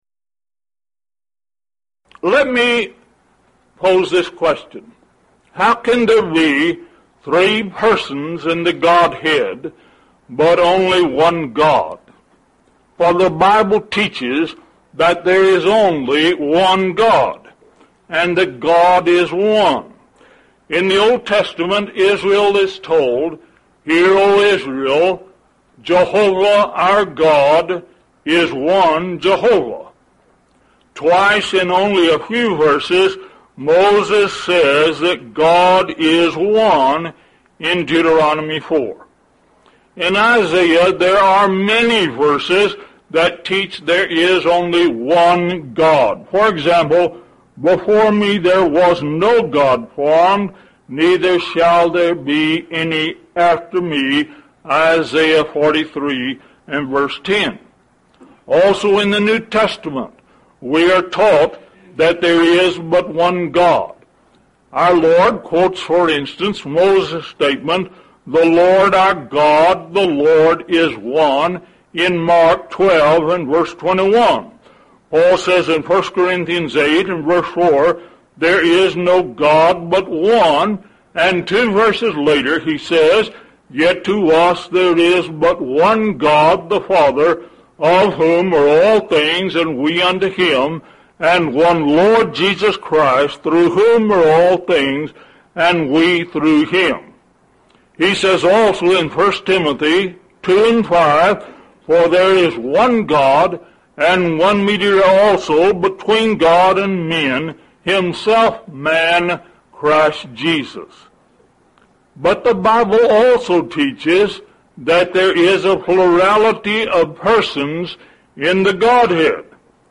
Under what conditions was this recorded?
Event: 1st Annual Lubbock Lectures